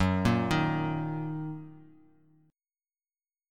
Gb6 Chord
Listen to Gb6 strummed